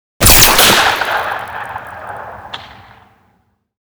Railgun_Near_01.ogg